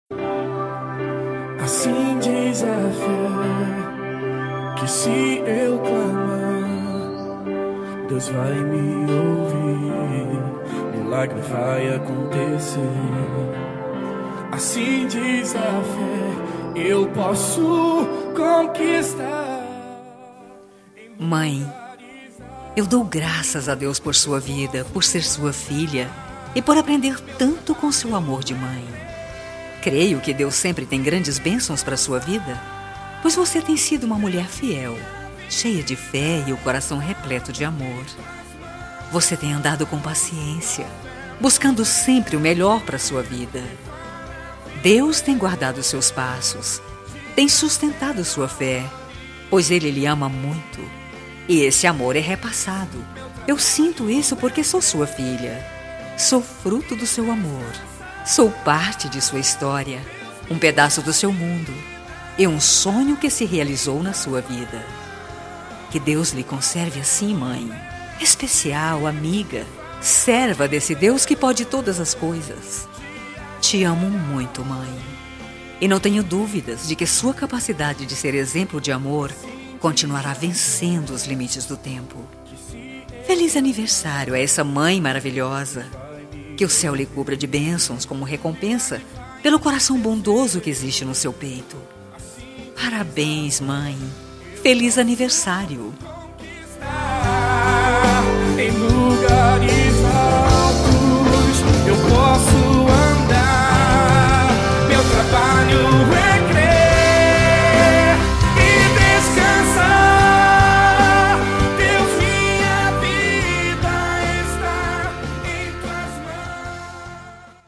Voz Feminina